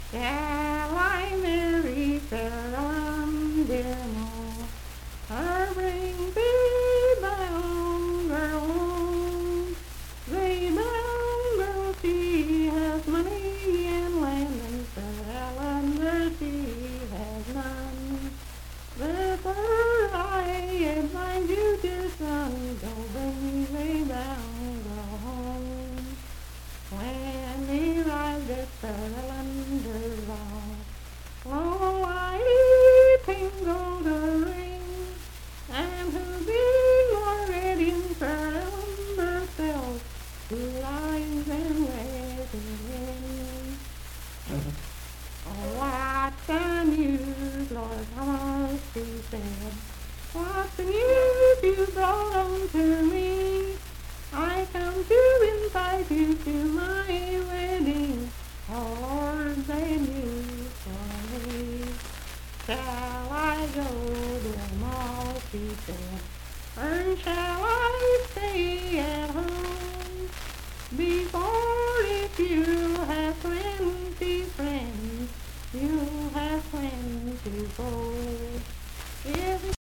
Unaccompanied vocal music
Verse-refrain 4(4-6).
Voice (sung)
Hardy County (W. Va.), Moorefield (W. Va.)